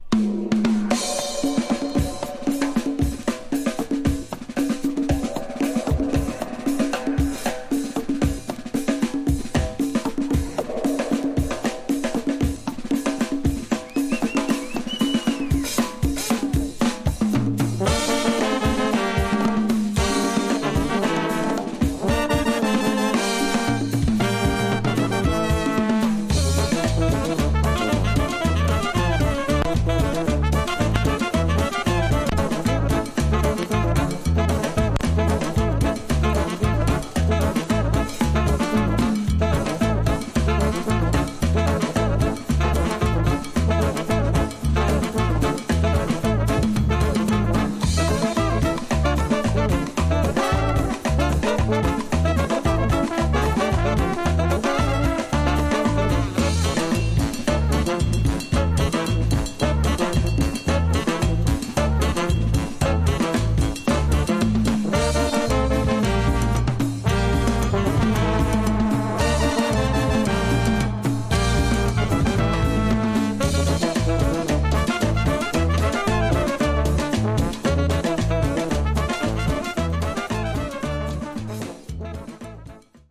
Tags: Brass , Psicodelico , Colombia , Bogotá